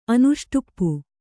♪ anuṣṭupu